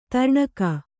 ♪ tarṇaka